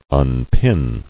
[un·pin]